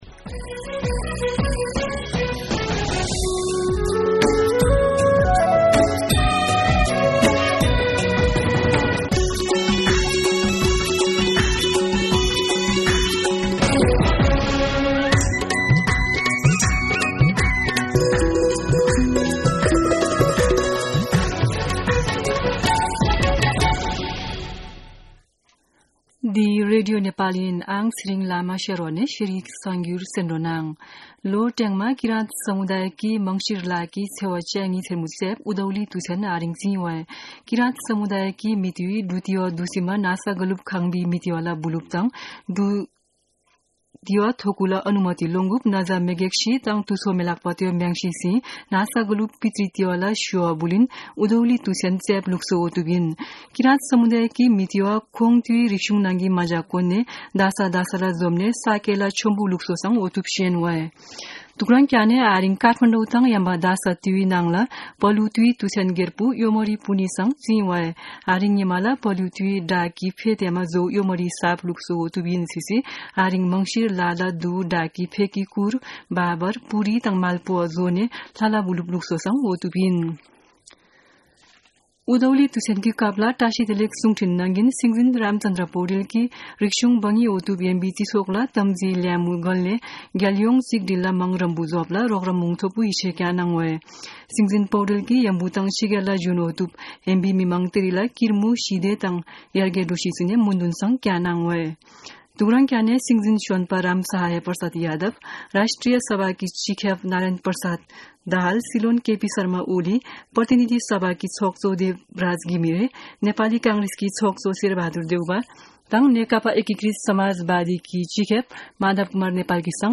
शेर्पा भाषाको समाचार : १ पुष , २०८१
Sherpa-News.mp3